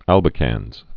(ălbĭ-kănz)